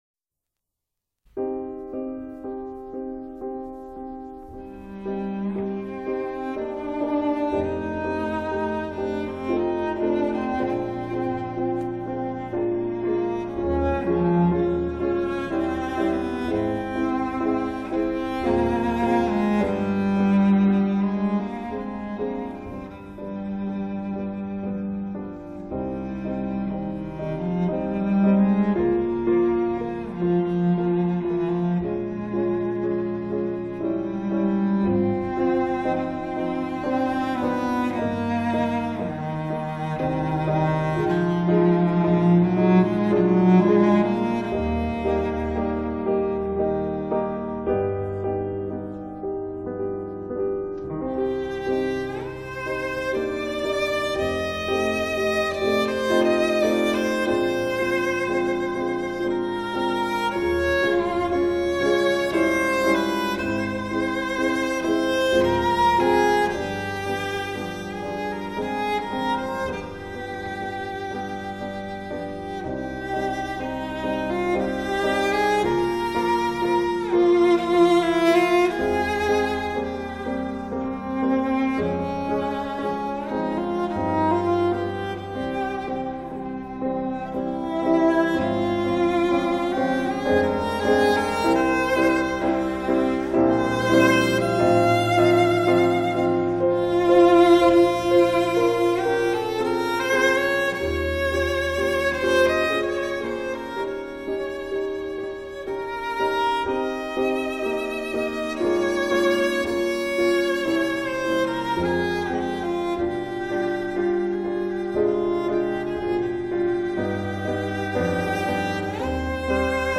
G. Fauré - Après un rêve op. 7 1 sâng för röst och piano